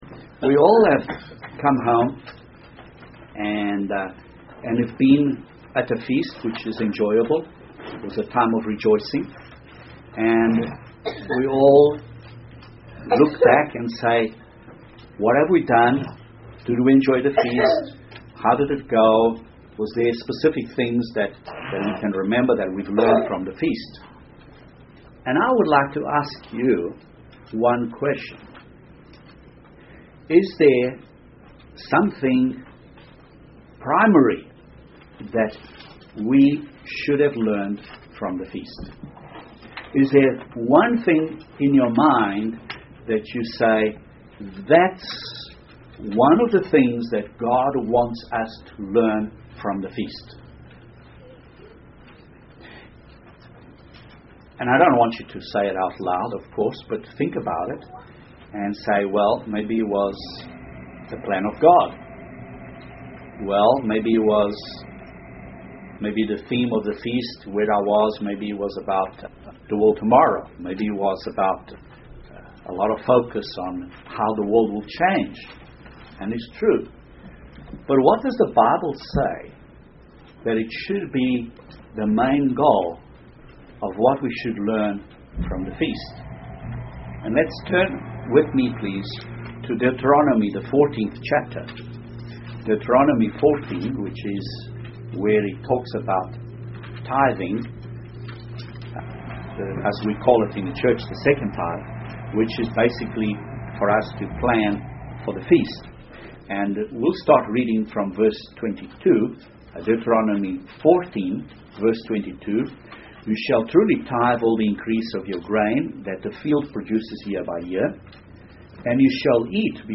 Learning the fear of God is one of the purposes of the Feast as per Deut 14. This sermon analyses what the fear of God is, shows what it leads us to do, and exhorts us to learn more from the Bible, and more specifically the Law and Christ's teachings.